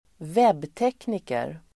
Ladda ner uttalet
webbtekniker substantiv (om datorer), web technician Uttal: [²v'eb:tek:niker] Böjningar: webbteknikern, webbtekniker, webbteknikerna Definition: den person som ansvarar för det tekniska på en webbplats